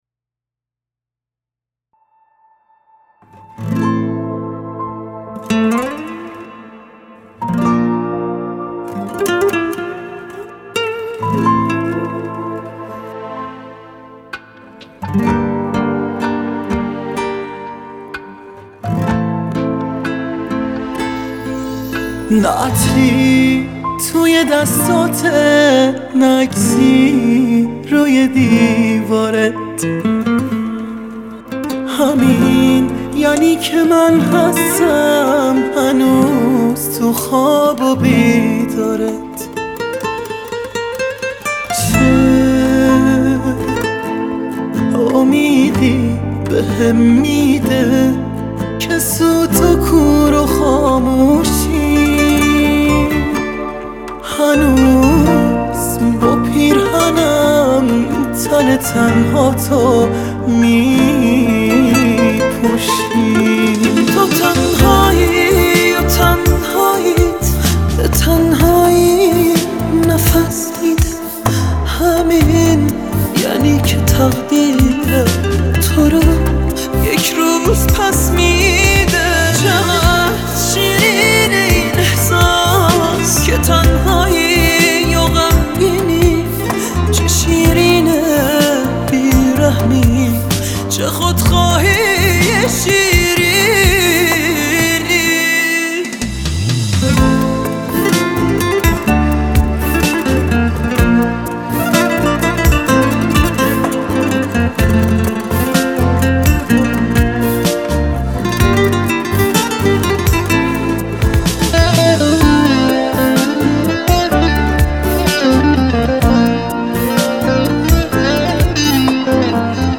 واااااااااییییییی چه آرامش بخش و عاشقانه بود.